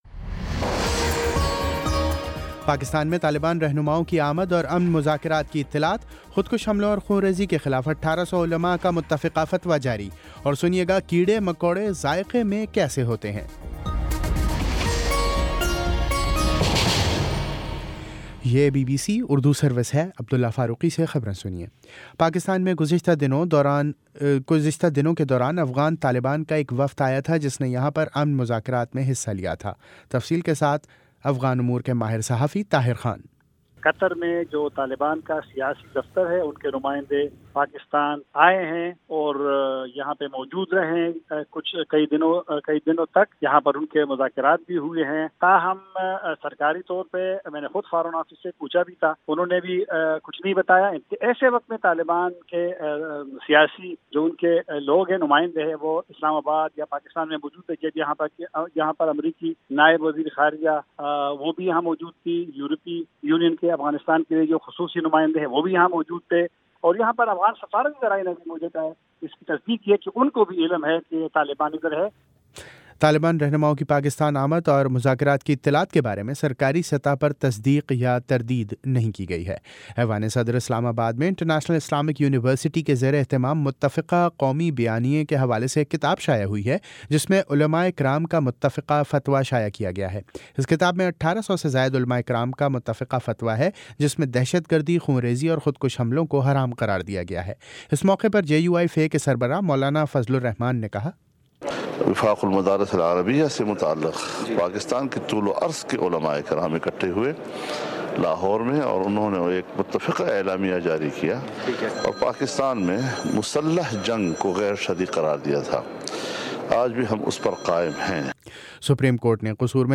دس منٹ کا نیوز بُلیٹن روزانہ پاکستانی وقت کے مطابق شام 5 بجے، 6 بجے اور پھر 7 بجے۔